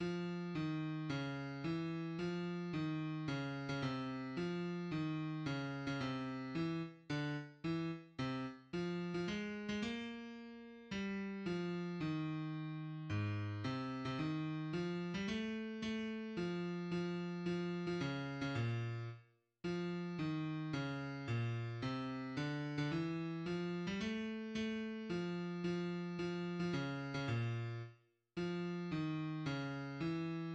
{\clef bass \tempo 4 = 110 \key aes \major \set Score.currentBarNumber = #13 \bar "" f ees des e f ees des8. des16 c4 f ees des8. des16 c4 e-. d-. e-. c-. f8. f16 aes8. aes16 bes2 g4 f ees2 aes,4 c8. c16 ees4 f8. g16 a4 a f f f8. f16 des8. des16 bes,4 r4 f ees des bes, c d8. d16 e4 f8. g16 a4 a f f f8. f16 des8. des16 bes,4 r4 f ees des e }\addlyrics {\set fontSize = #-2 - - - - Zog nit key - nmol, Zog nit key - nmol, Zog nit key- nmol geyst dem let- stn veg Him- len far- shteln bloy- e teg Ku- men vet nokh un- zer oys- ge- benk- te sho Trot mir zayn- en do zayn- en do Ku- men vet nokh un- zer oys- ge- benk- te sho } \addlyrics {\set fontSize = #-2 un- zer trot Ge- shri- ben is dos lid blut un nit mit blay iz dos lid mit blut un nit mit blay Fun a foy gl oyf der fray, S'hot a folk ts'- vi- shn fa- ln- di- ke vent, Mit na- gan- es hent in di hent }\midi{}